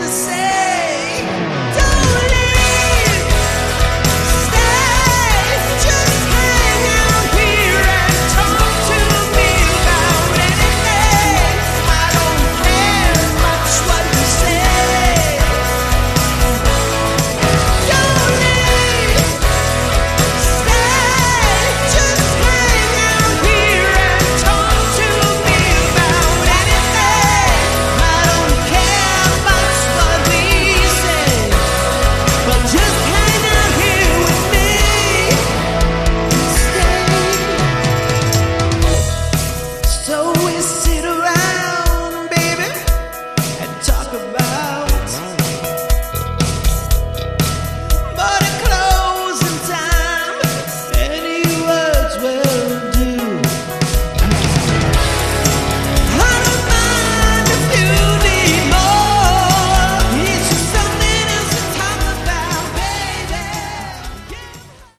Category: AOR
lead vocals, acoustic guitar
drums, percussion, backing vocals